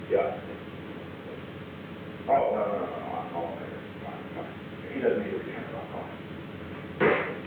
Secret White House Tapes
Location: Executive Office Building
An unknown person talked with the President.